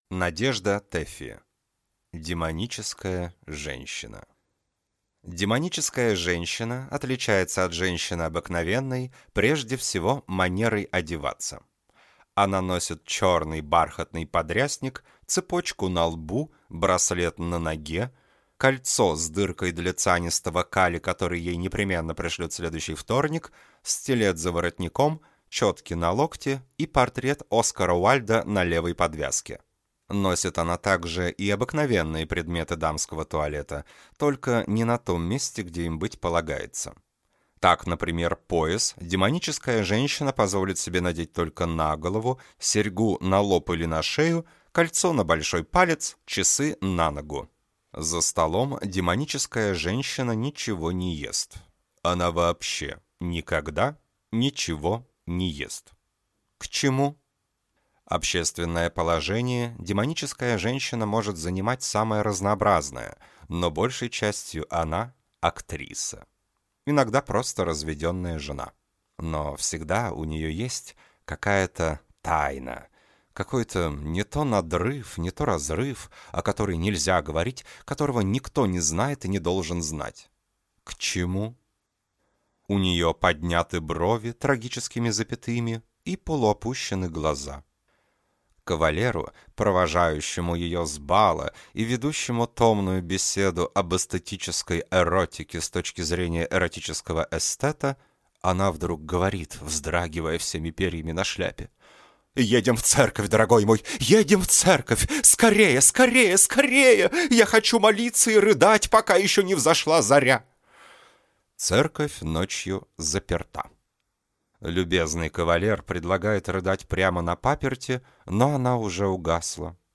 Аудиокнига Демоническая женщина | Библиотека аудиокниг